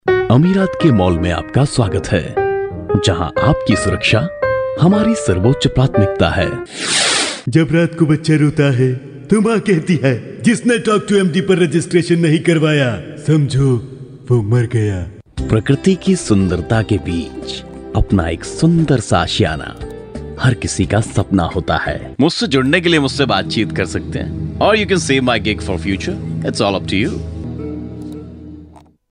乌尔都语中年大气浑厚磁性 、沉稳 、娓娓道来 、积极向上 、男专题片 、宣传片 、纪录片 、广告 、飞碟说/MG 、课件PPT 、工程介绍 、绘本故事 、动漫动画游戏影视 、旅游导览 、微电影旁白/内心独白 、80元/百单词男印01 乌尔都语男声 大气浑厚磁性|沉稳|娓娓道来|积极向上